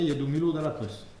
Patois
Locution